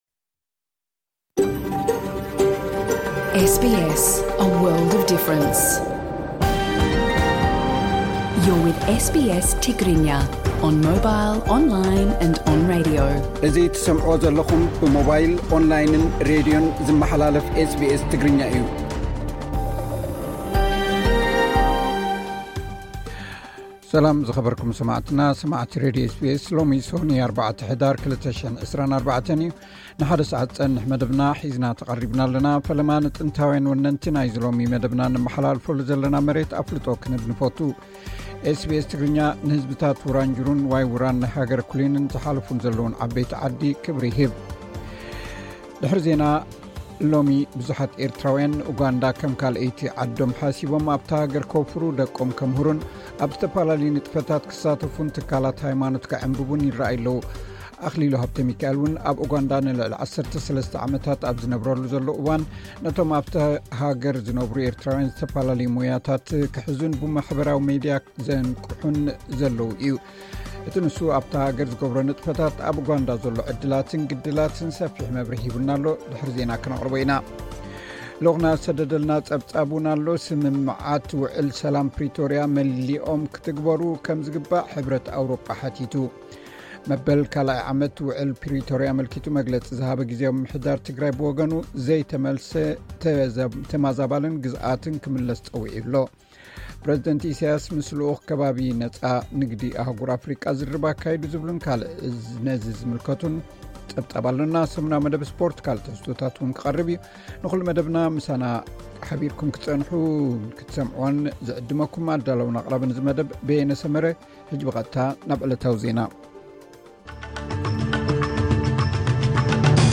ቀጥታ ምሉእ ትሕዝቶ ፖድካስት ኢአስ ቢ ኤስ ትግርኛ (04 ሕዳር 2024)